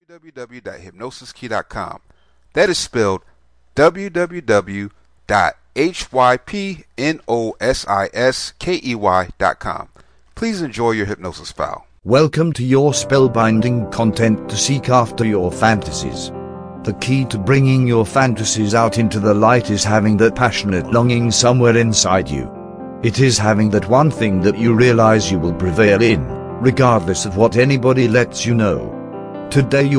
Welcome to pursue your dreams Self Hypnosis Mp3, this is a powerful hypnosis. This mp3 helps you meditate and believe in yourself enough to pursue your dreams.